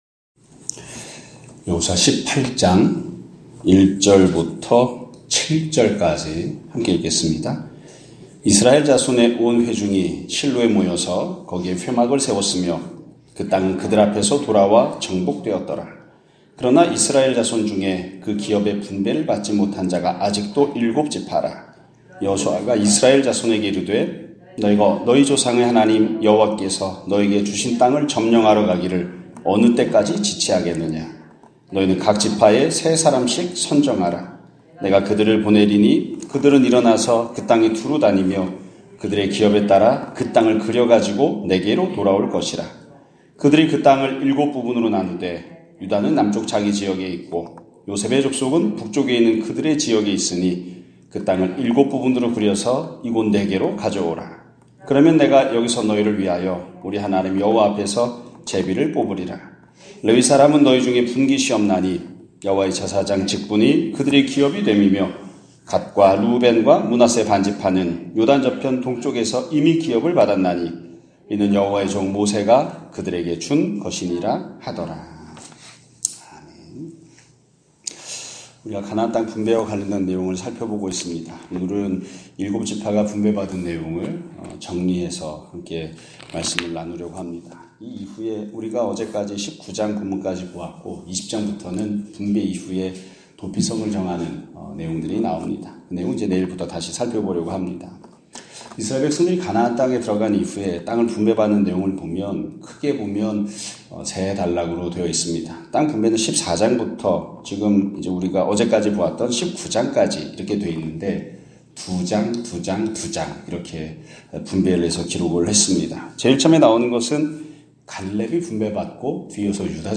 2025년 1월 14일(화요일) <아침예배> 설교입니다.